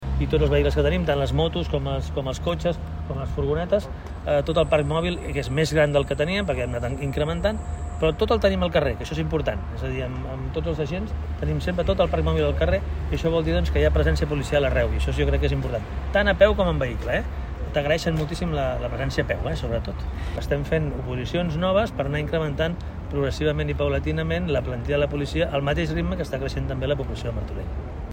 Xavier Fonollosa, alcalde de Martorell